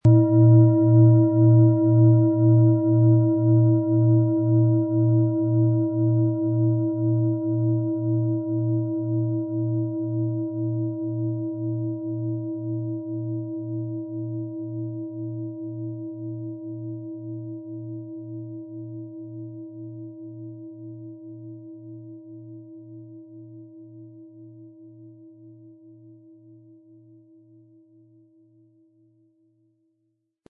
• Tiefster Ton: Mond
Im Sound-Player - Jetzt reinhören können Sie den Original-Ton genau dieser Schale anhören.
PlanetentöneHopi Herzton & Mond
MaterialBronze